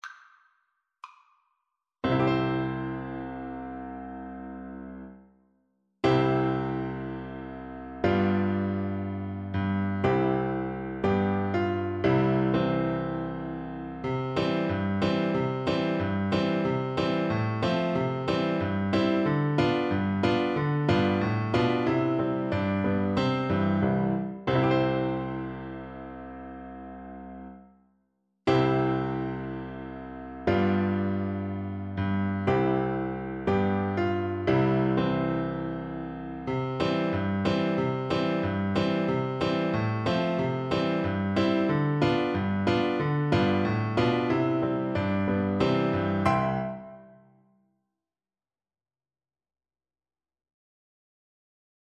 Christmas
Slow =c.60
2/2 (View more 2/2 Music)